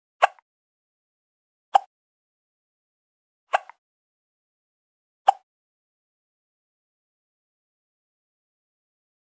دانلود آهنگ سکسکه 3 از افکت صوتی انسان و موجودات زنده
دانلود صدای سکسکه 3 از ساعد نیوز با لینک مستقیم و کیفیت بالا
جلوه های صوتی